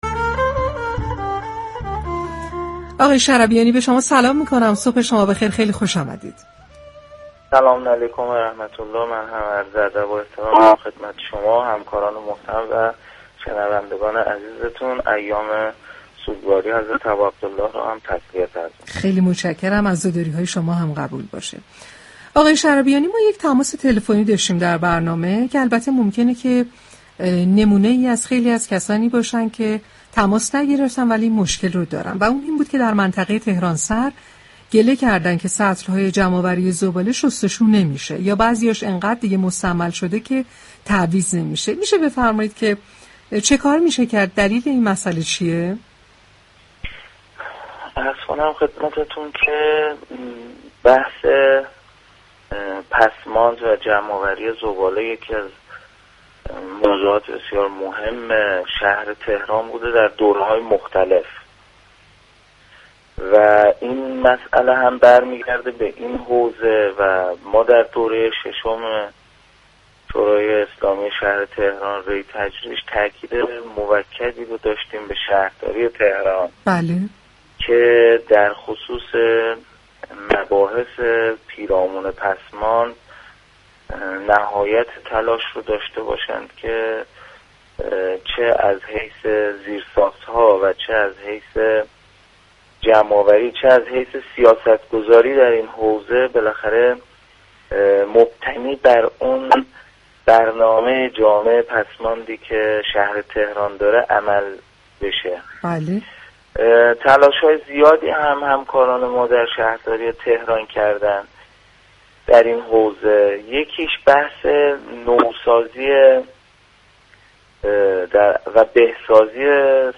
به گزارش پایگاه اطلاع رسانی رادیو تهران، جعفر شربیانی نایب رئیس كمیسیون معماری و شهرسازی شورای اسلامی شهر تهران در گفت و گو با «شهر آفتاب» اظهار داشت: شهرداری تهران درخصوص مدیریت پسماند‌ها وظایف متعددی بر عهده دارد كه یكی از این موارد نوسازی و بهسازی سطل‌های زباله شهری است كه به مرور در مناطق محتلف شهرداری در حال اجرا است.